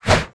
su_swing_1.wav